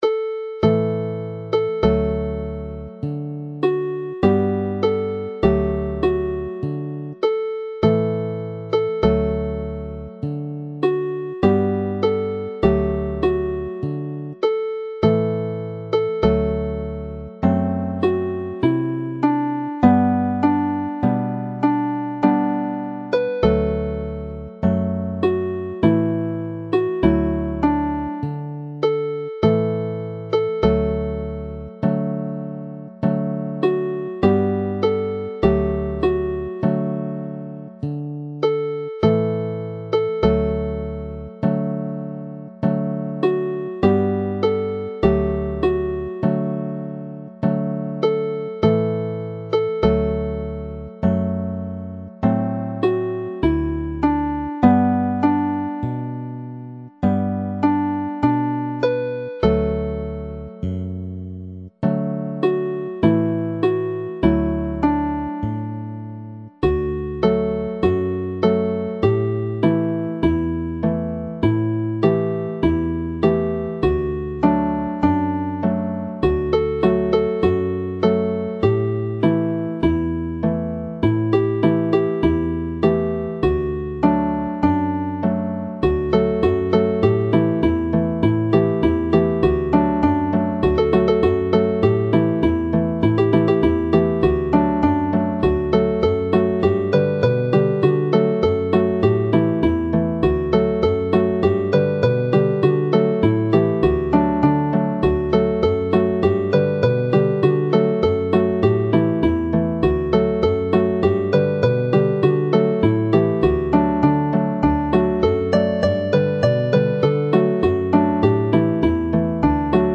Alawon Cymreig - Set Ar Lan y Môr set - Welsh folk tunes
Two different timings are given here, 3 or 4 beats to the bar, as both versions are sung, depending on how long the word Môr is sustained.